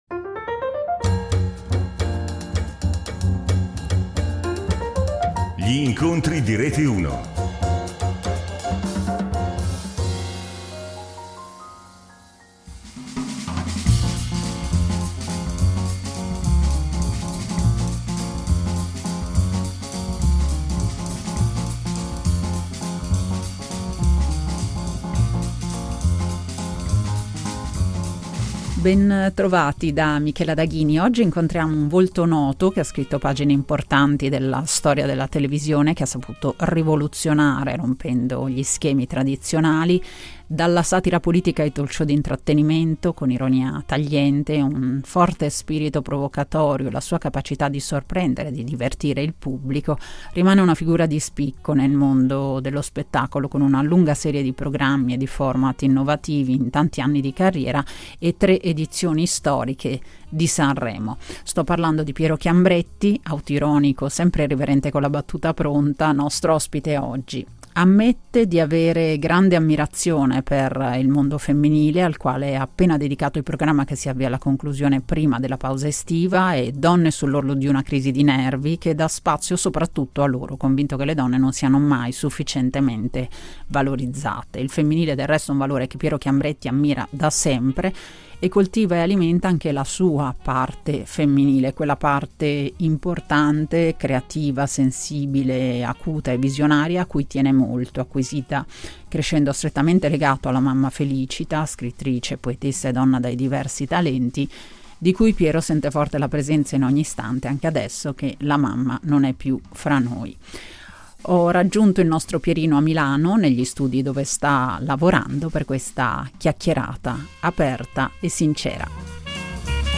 Gli Incontri di Rete Uno